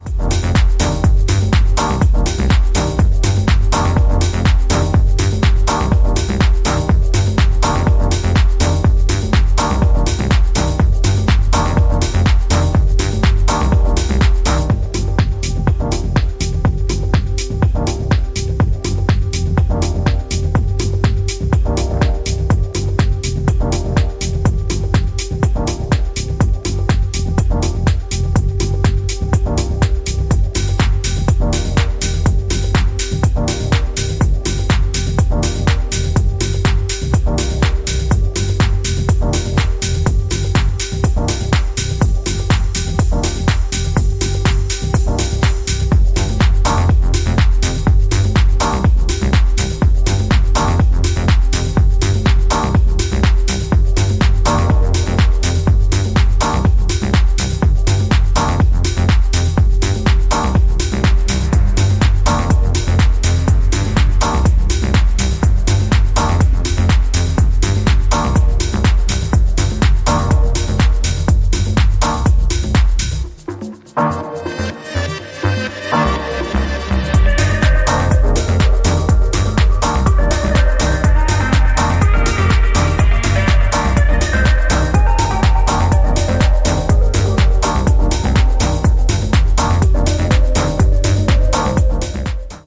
ジャンル(スタイル) DEEP HOUSE / DETROIT